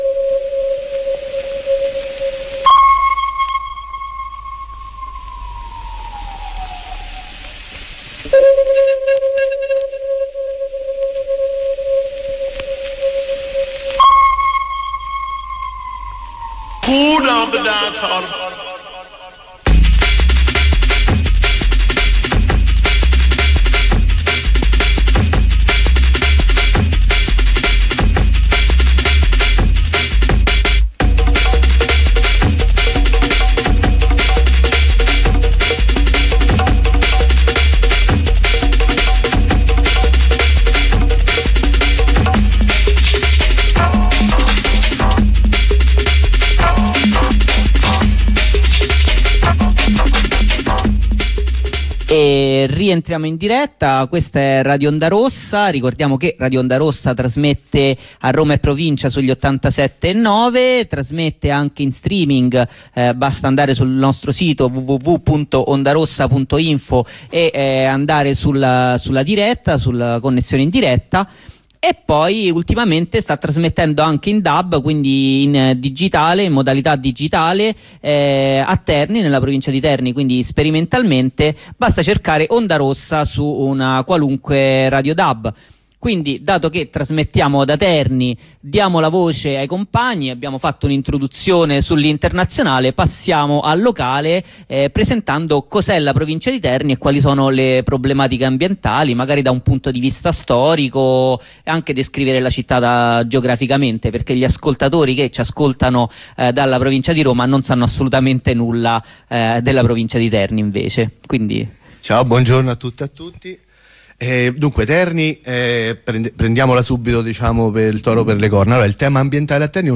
Corrispondenza dalla piazza di Cagliari